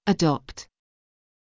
• əˈdɒpt（英）：「アドプト」（「ド」にやや深い母音）